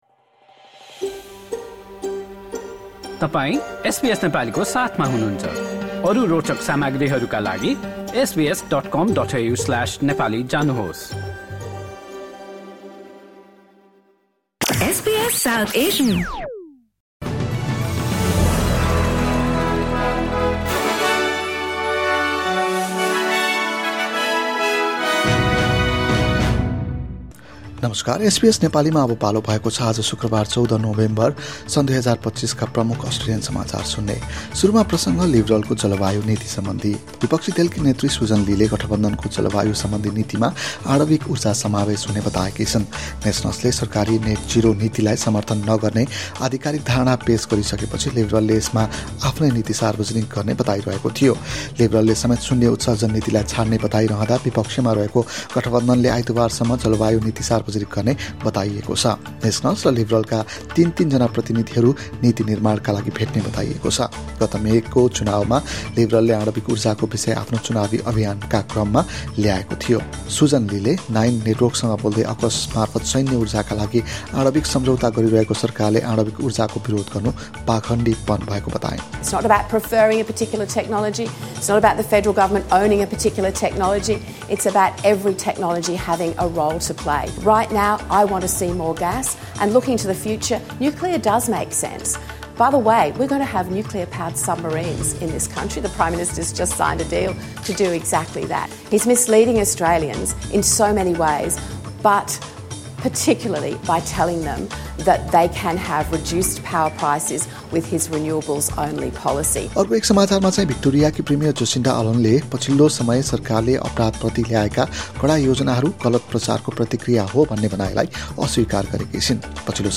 एसबीएस नेपाली प्रमुख अस्ट्रेलियन समाचार: शुक्रवार, १४ नोभेम्बर २०२५